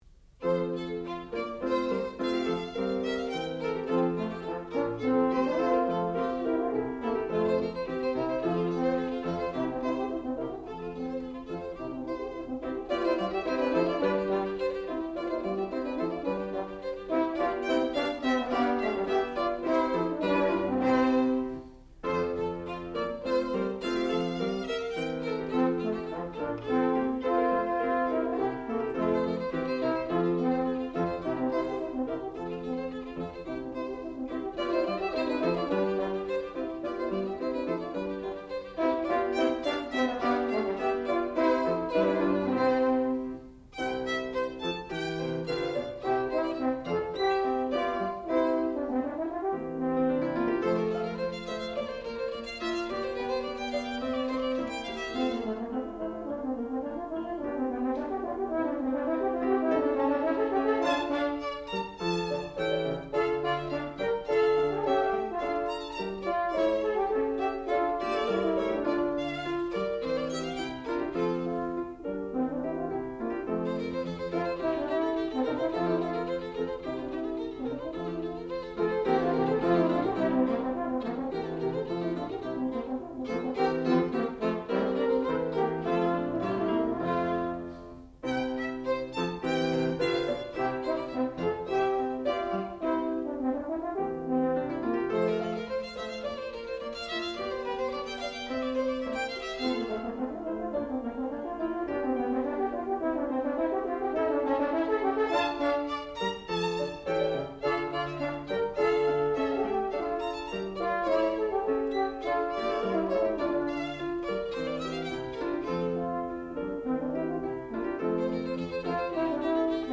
sound recording-musical
classical music
violin
piano
horn